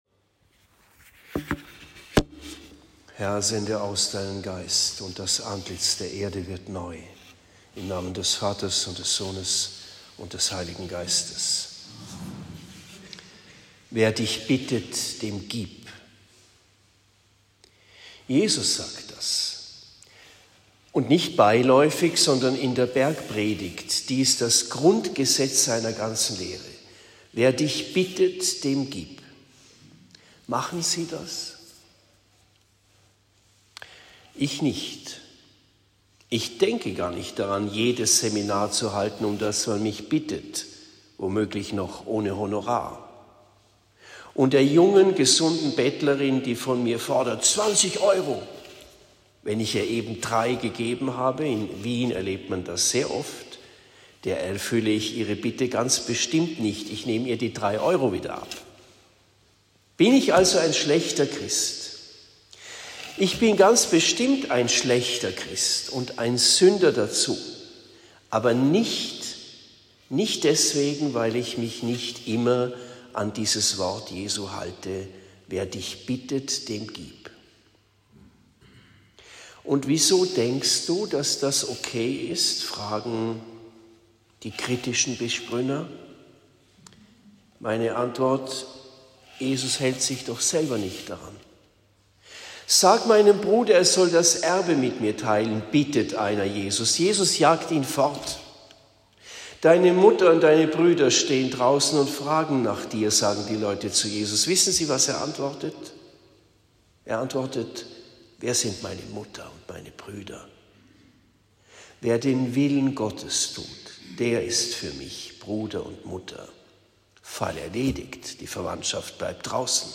7. Sonntag im Jahreskreis – Predigt in Bischbrunn